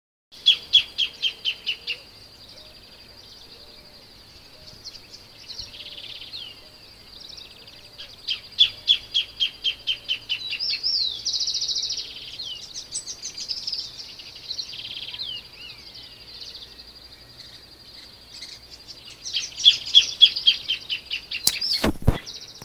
Graveteiro (Phacellodomus ruber)
Nome em Inglês: Greater Thornbird
Fase da vida: Adulto
Localidade ou área protegida: Parque Natural Municipal Ribera Norte (San Isidro)
Condição: Selvagem
Certeza: Fotografado, Gravado Vocal